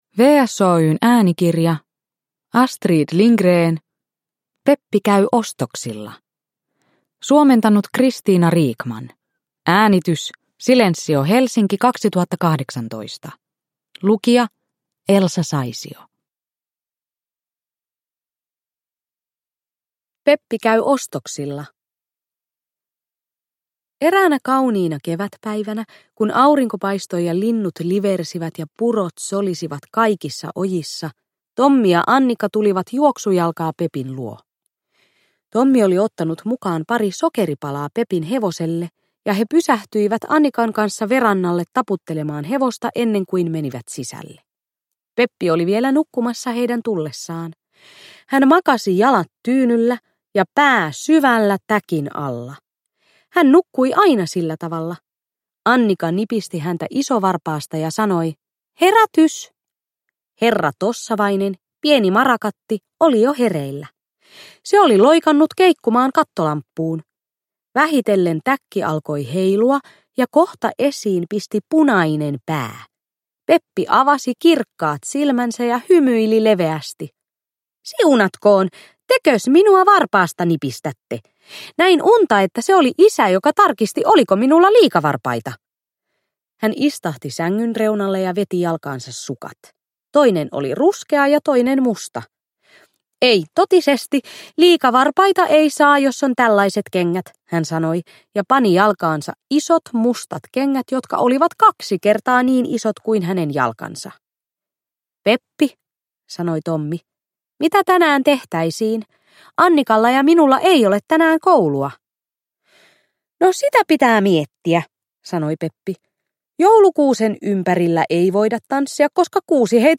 Peppi käy ostoksilla – Ljudbok – Laddas ner